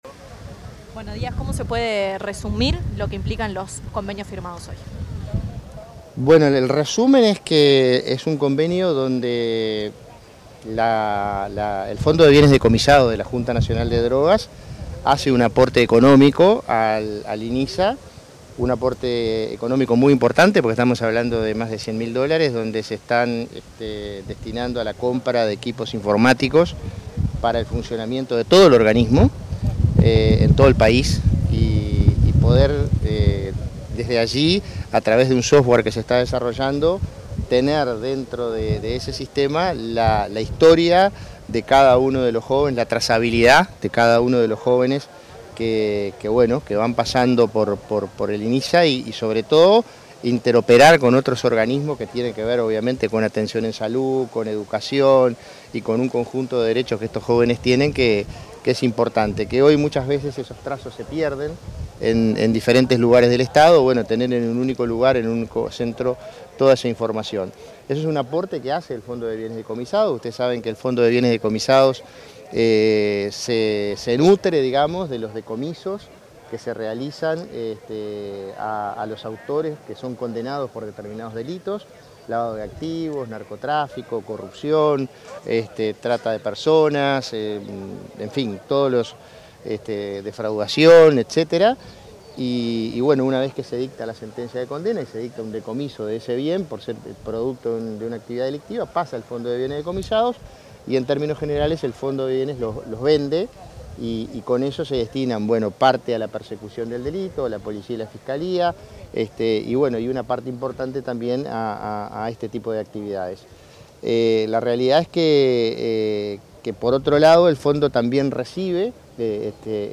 Declaraciones del prosecretario de la Presidencia y presidente de la JND, Jorge Díaz
Declaraciones del prosecretario de la Presidencia y presidente de la JND, Jorge Díaz 11/11/2025 Compartir Facebook X Copiar enlace WhatsApp LinkedIn Tras la firma de un acuerdo entre la Junta Nacional de Drogas (JND) y el Instituto Nacional de Inclusión Social Adolescente (Inisa), se expresó ante los medios de prensa el prosecretario de la Presidencia, Jorge Díaz, en su calidad de presidente de la JND.